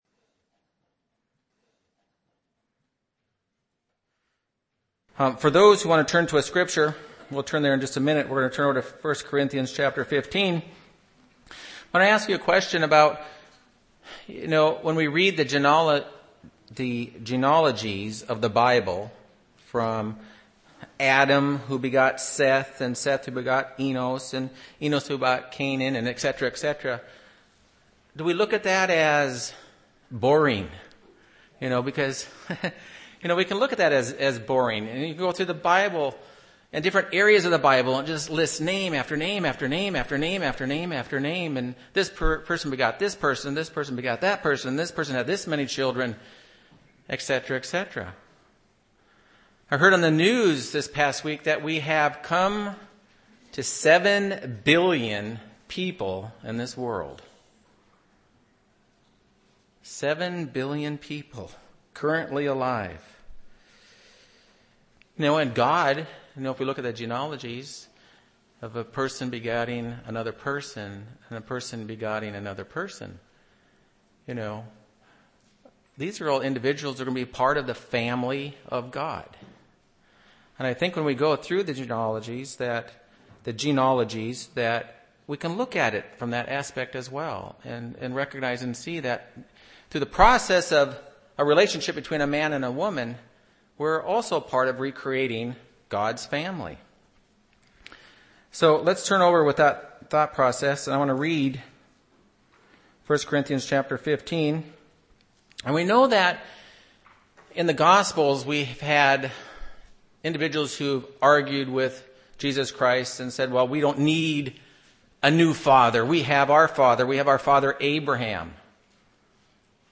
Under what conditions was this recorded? Given in Phoenix East, AZ